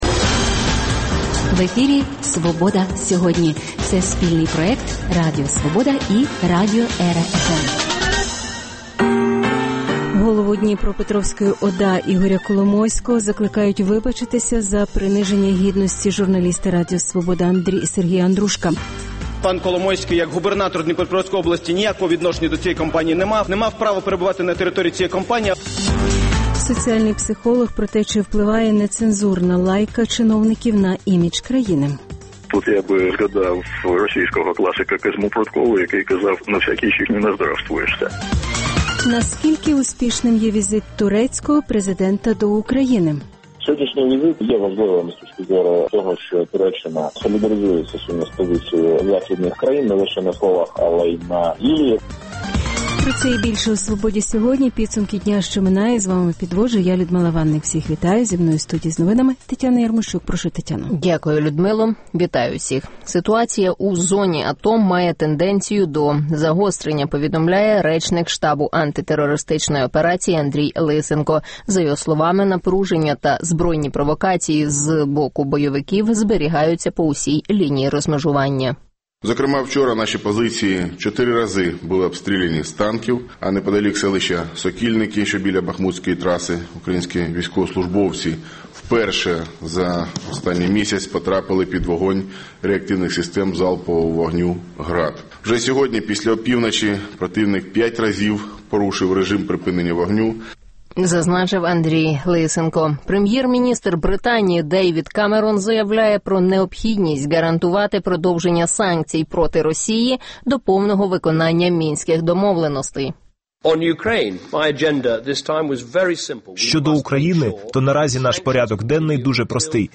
Наскільки успішним є візит турецького президента до України? У Києві розпочався 12-й міжнародний фестиваль документального кіно про права людини Docudays UA – пряме включення